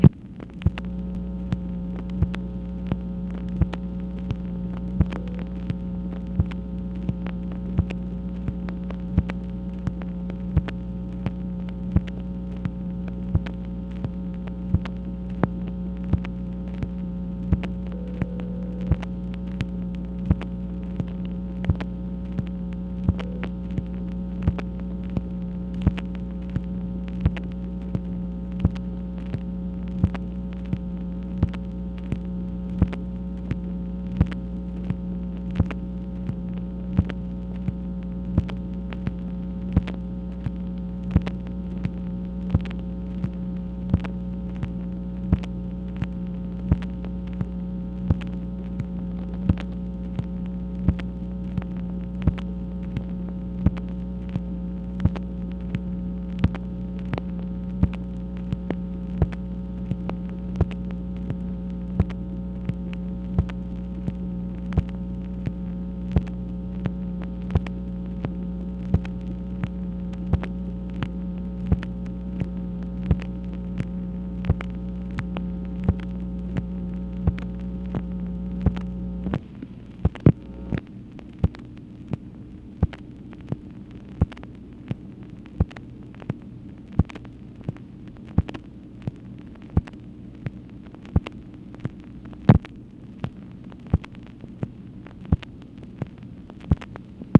MACHINE NOISE, September 12, 1966
Secret White House Tapes | Lyndon B. Johnson Presidency